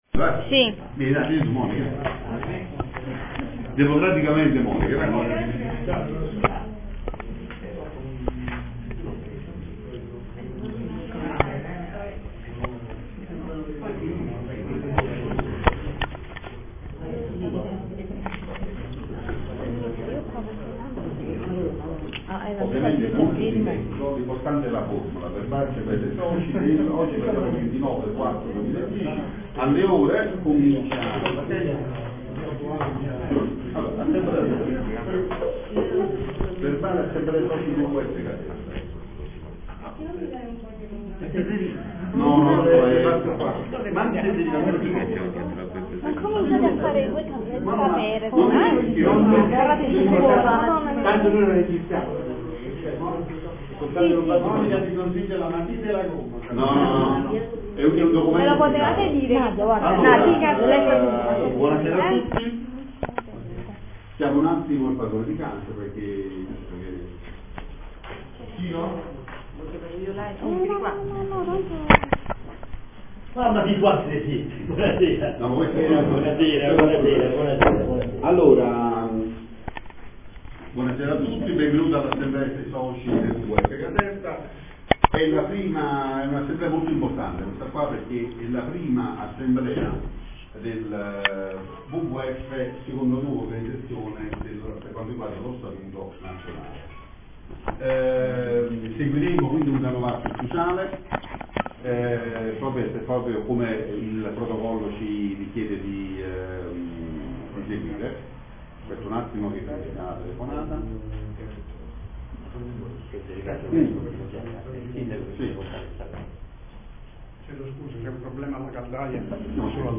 ( scarica la registrazione dell'assemblea )
Alle 18,20 circa si � aperta l'assemblea dei Soci del WWF Caserta, la prima vera assemblea dopo la nascita dell'Associazione locale.